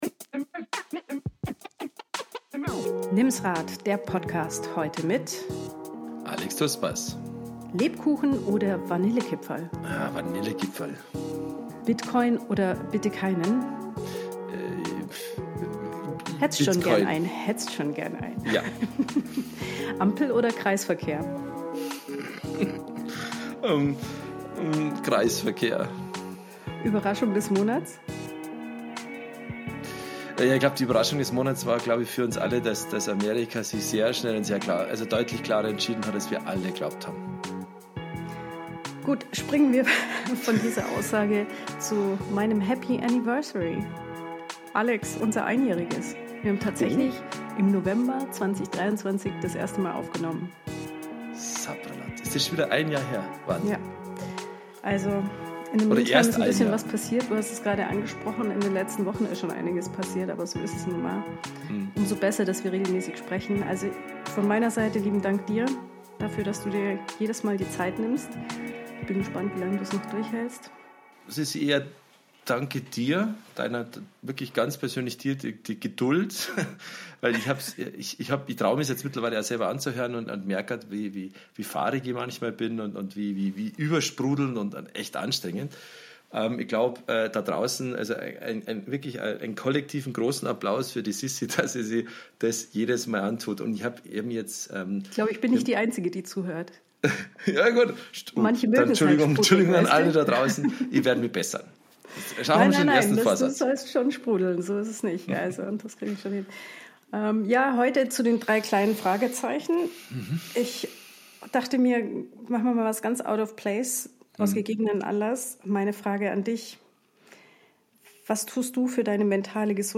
Beschreibung vor 1 Jahr Ein Talk, der so angenehm dahinraschelt wie das Herbstlaub unter unseren Füßen.